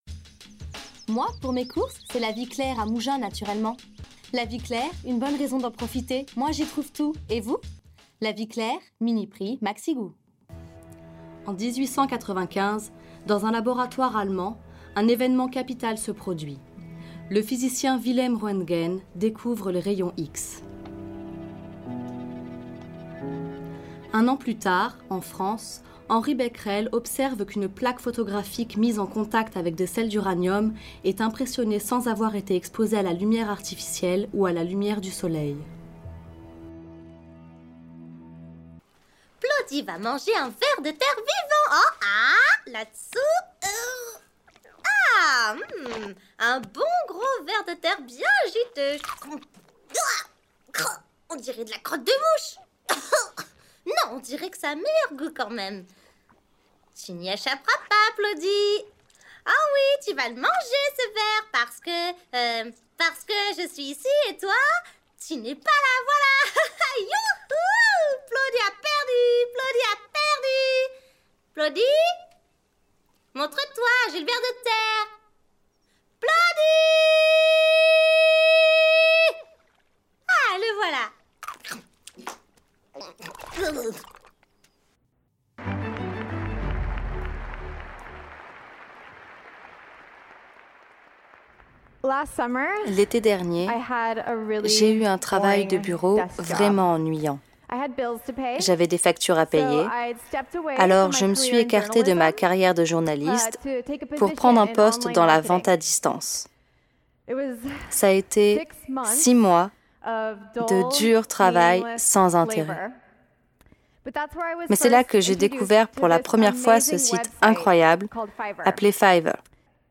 Voix off
Publicité / Institutionnel / Narration